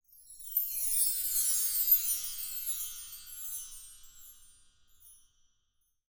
chimes.wav